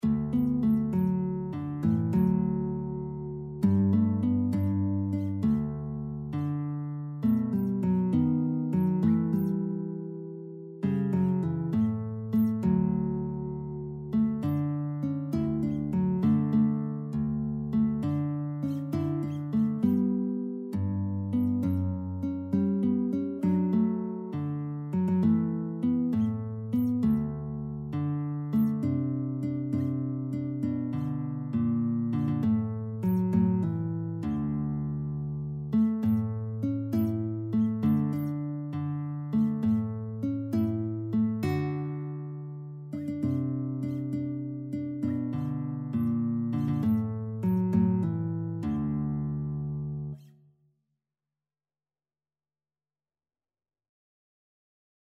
Guitar version
6/8 (View more 6/8 Music)
Guitar  (View more Intermediate Guitar Music)
Classical (View more Classical Guitar Music)